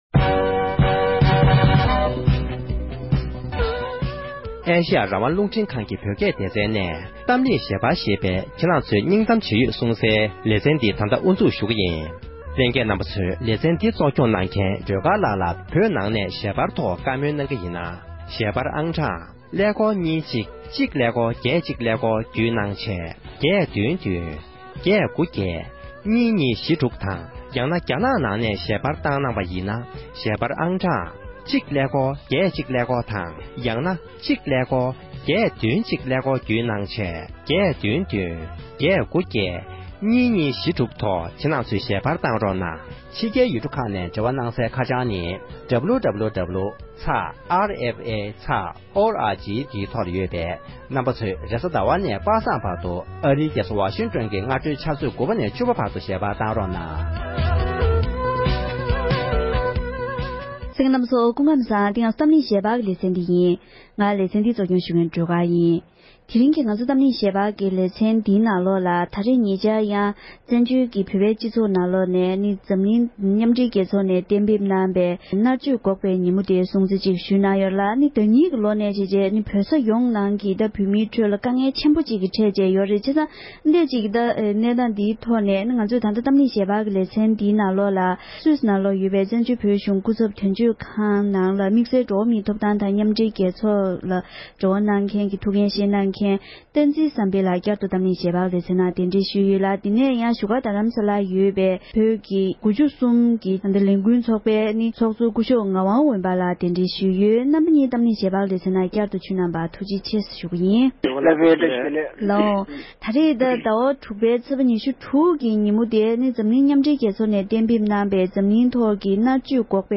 གཏམ་གླེང་ཞལ་པར་གྱི་ལེ་ཚན